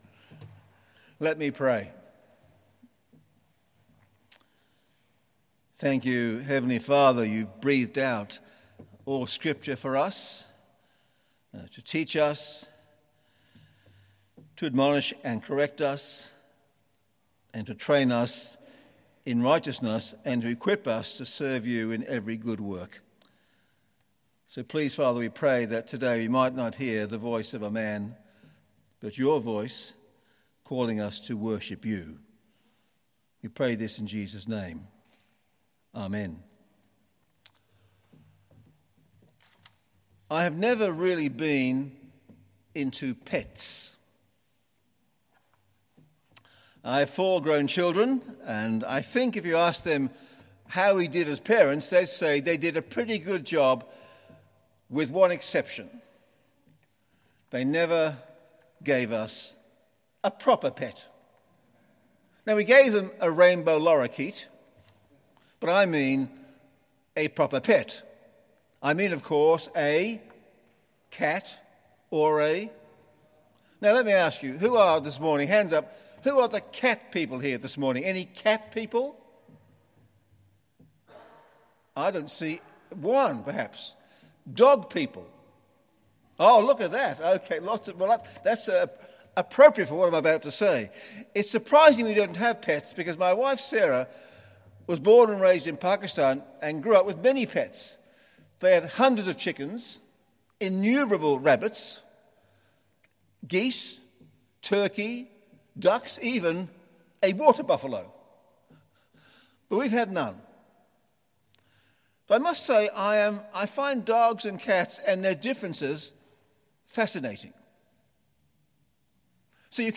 Full Service Audio
The Scots’ Church Melbourne 11am Service 27th of December 2020